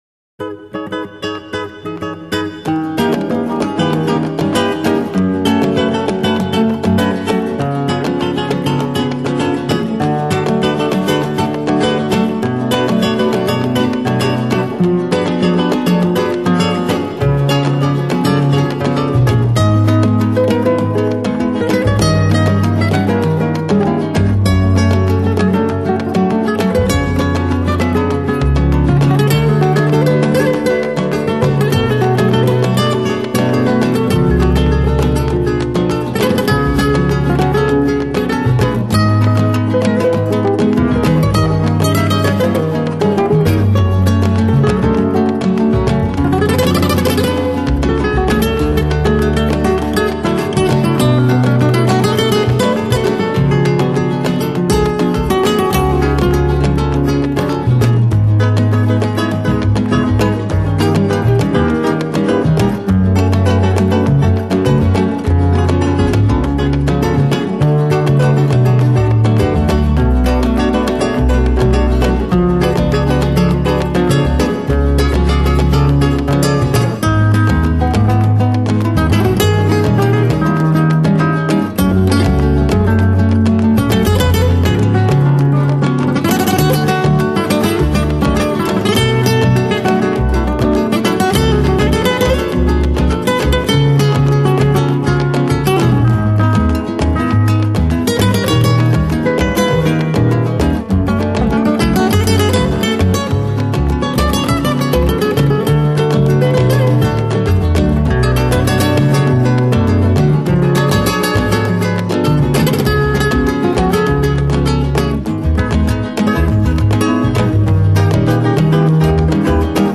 在演出的時候，六把吉他以疊層的方式表現出氣勢，配合上打擊樂器的演出，造就出動感十足的旋律。